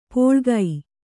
♪ pōḷgai